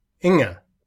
(The final -r is unpronounced in Denmark, just as in England; see SCEPLog 5.)